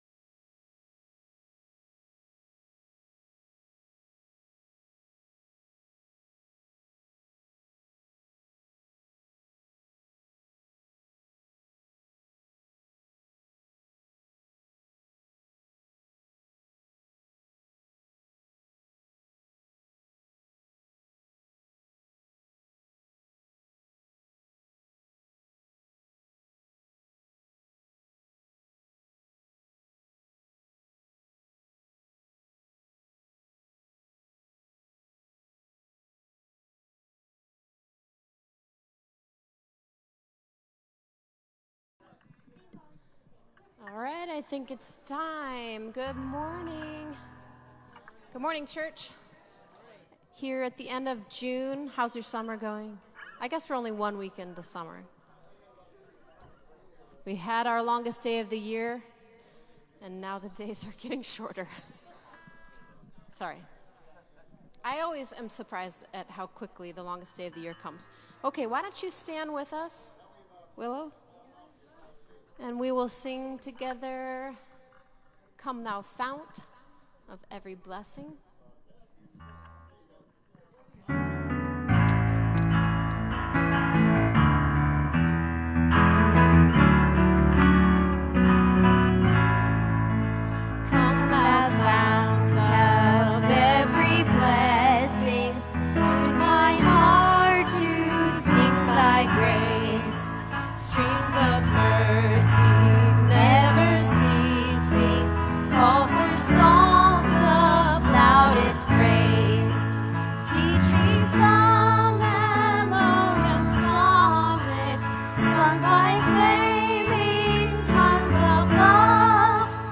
Praise Worship
Service Opening